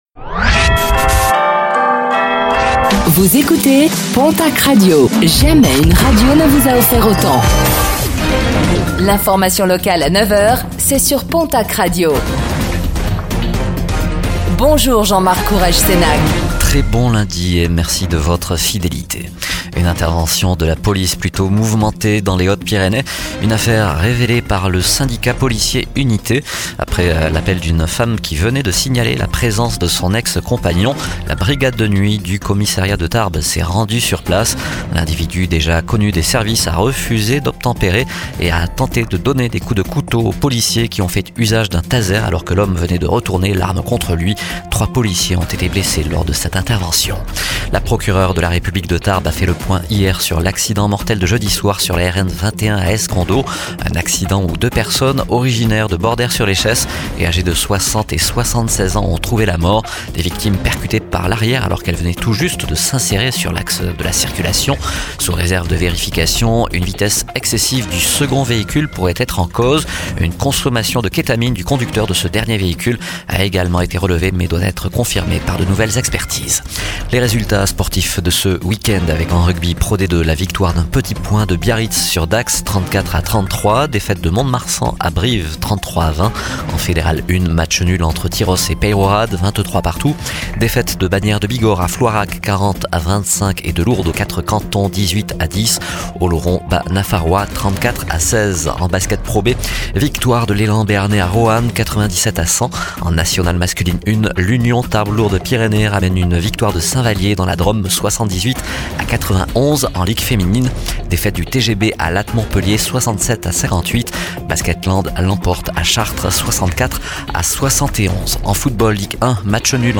09:05 Écouter le podcast Télécharger le podcast Réécoutez le flash d'information locale de ce lundi 10 mars 2025